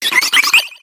Cries
WOOBAT.ogg